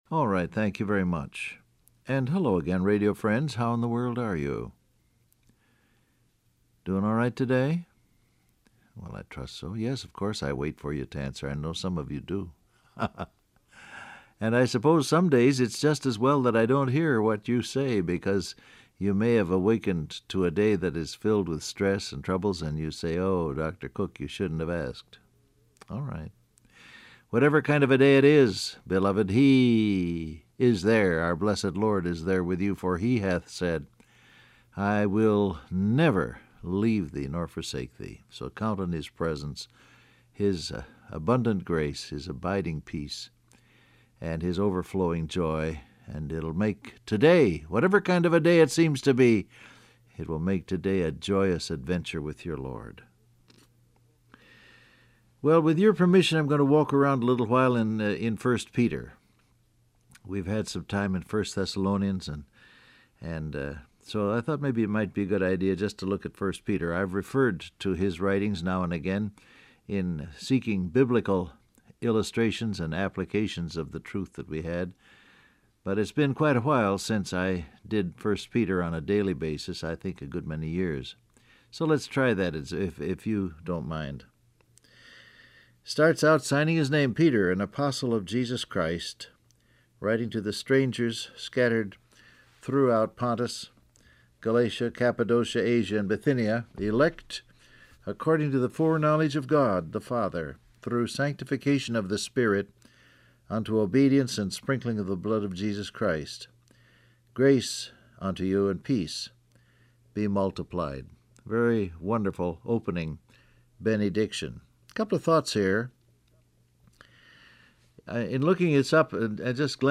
Download Audio Print Broadcast #7117 Scripture: 1 Peter 1:1-2 , Acts 8:1 Topics: Chosen , Predestination , Free Will , Elect , Persecuted Christians Transcript Facebook Twitter WhatsApp Alright, thank you very much.